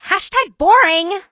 emz_kill_vo_05.ogg